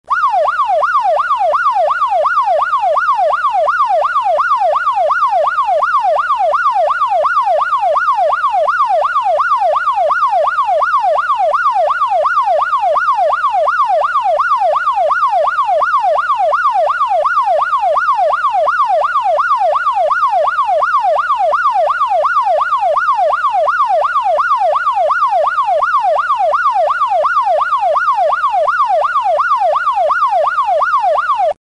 Police Siren Sound Effect Free Download
Police Siren